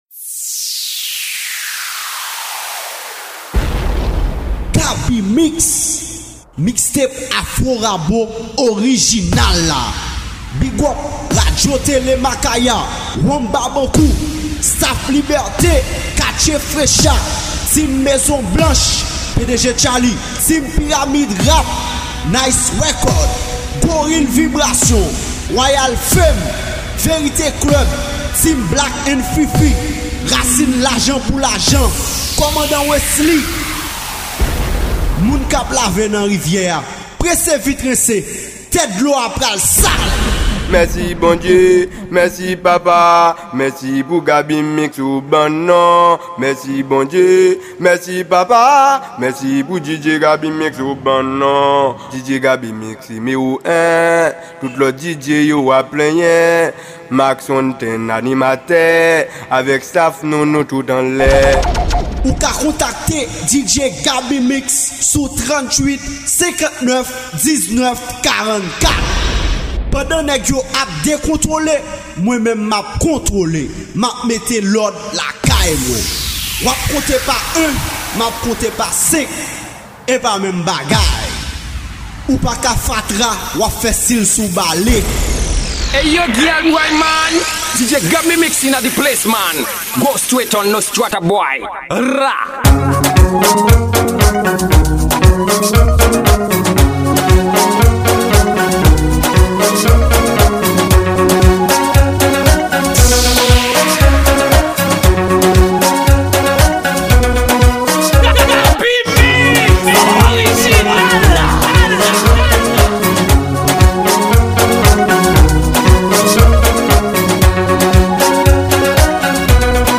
Genre: DJ Mixes.